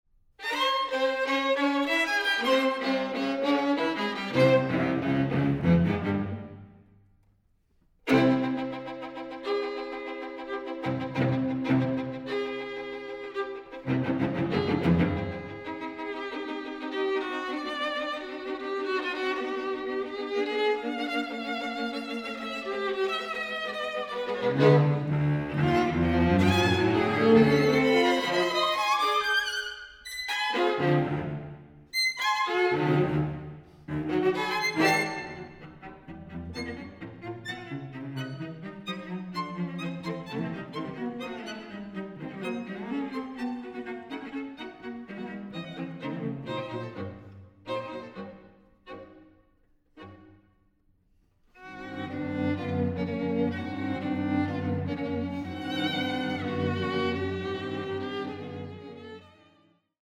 String Quartet
Recording: Deutschlandfunk Kammermusiksaal, Köln, 2019-2021